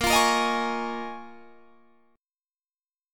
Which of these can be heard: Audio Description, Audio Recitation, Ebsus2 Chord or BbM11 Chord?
BbM11 Chord